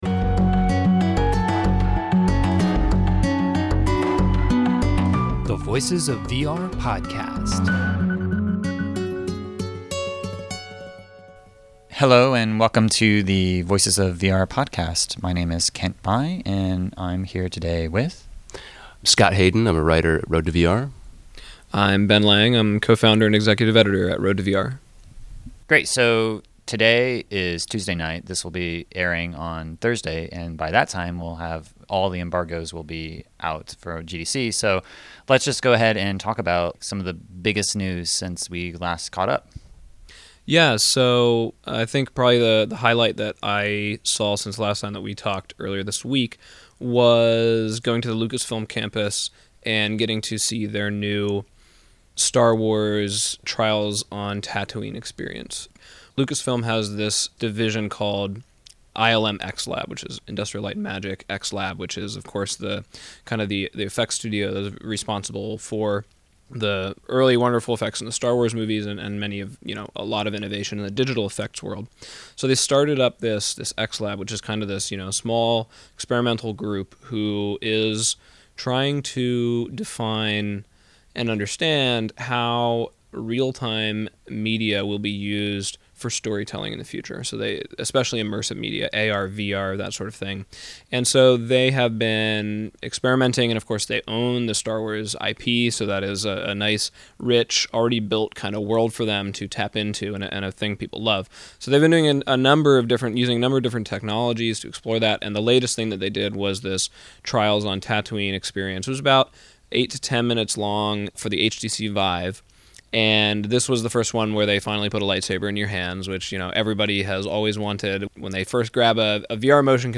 #322: Round Table Discussion on GDC Highlights, PlayStation VR, & Minecraft VR – Voices of VR Podcast